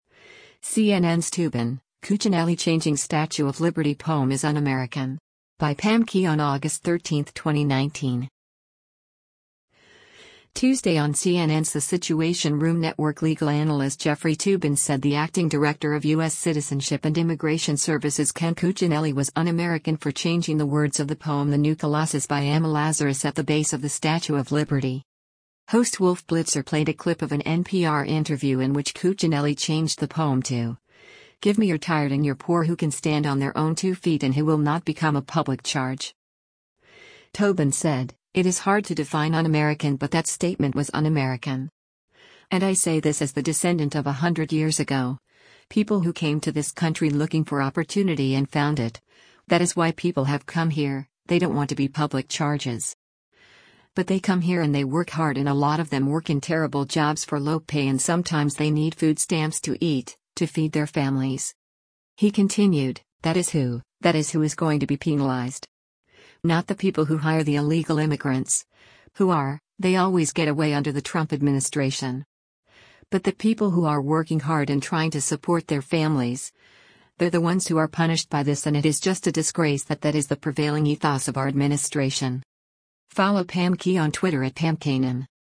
Host Wolf Blitzer played a clip of an NPR interview in which Cuccinelli changed the poem to, “Give me your tired and your poor who can stand on their own two feet and who will not become a public charge.”